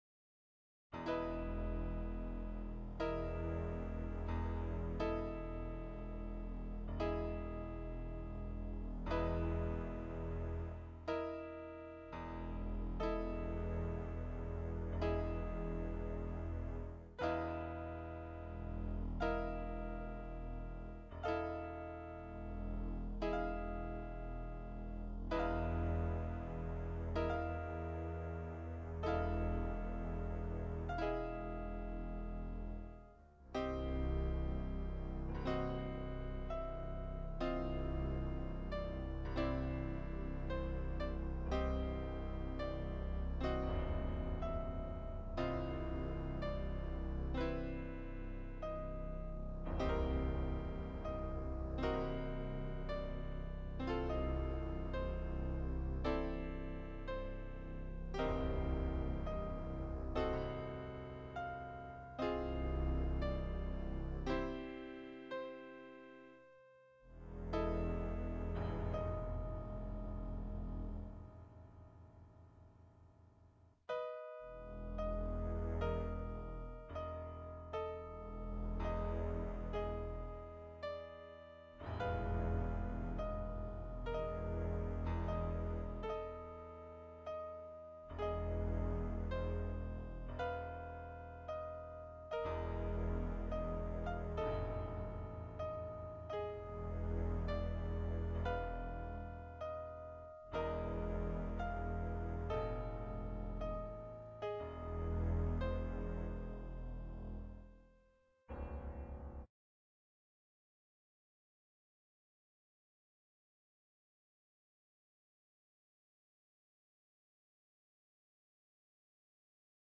BGM Piano Music with simple strings bass